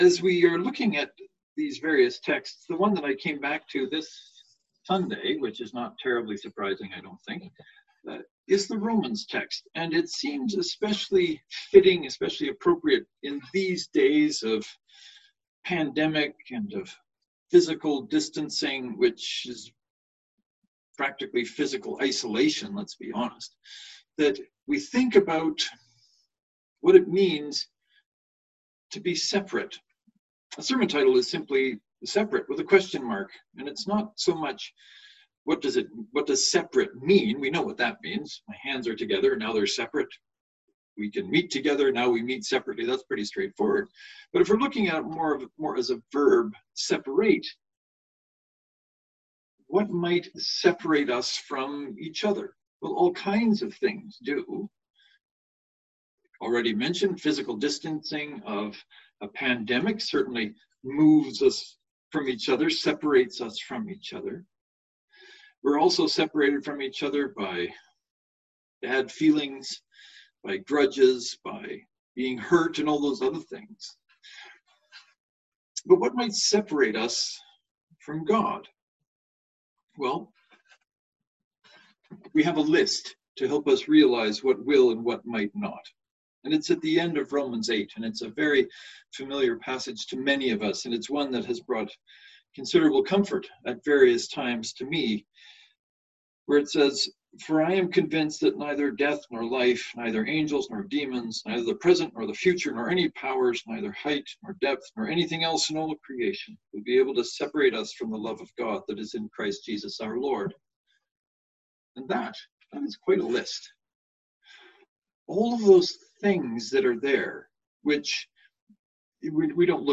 “Separate?” Knox and St. Mark’s Presbyterian joint service (to download, right click and select “Save Link As .